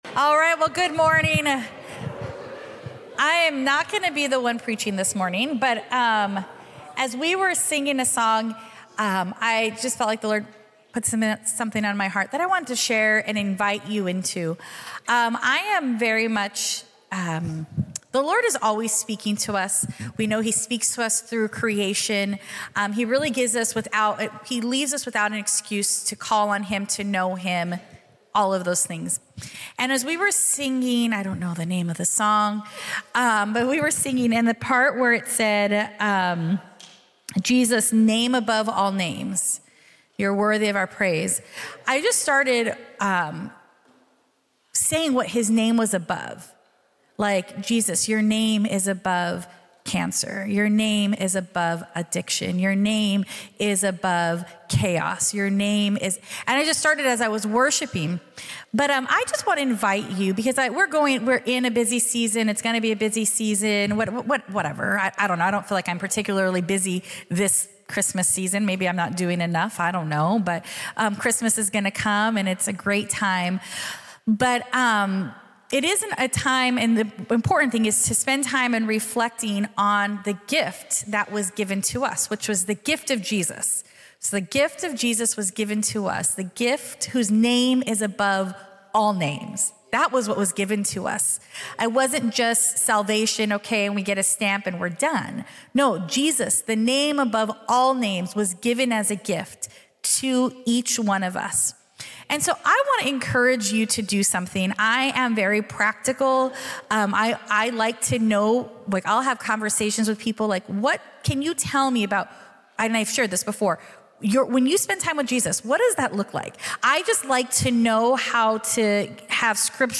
This sermon, "God Became Man," explores the profound truth of the incarnation—Jesus Christ taking on human flesh to reconcile humanity to God.